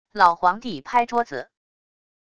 老皇帝拍桌子――wav音频